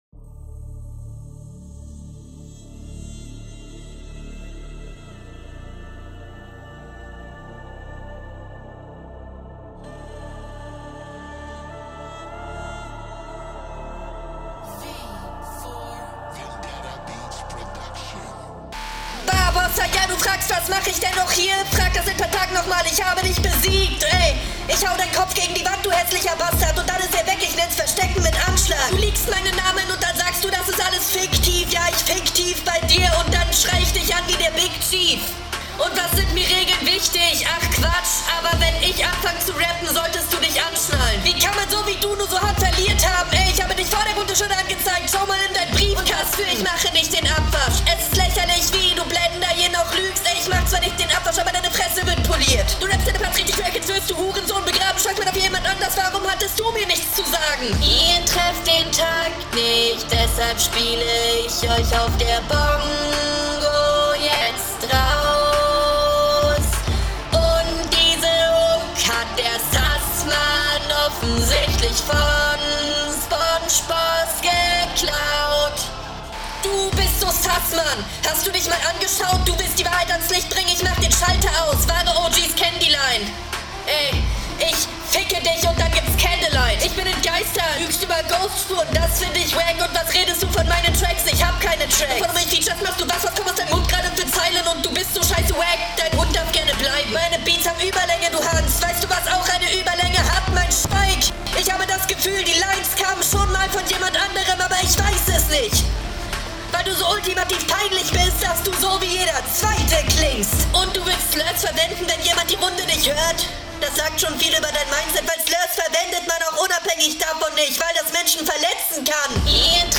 Der Flow ist wieder sehr off, aber wenigstens …